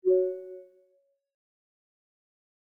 MRTK_Voice_Confirmation.wav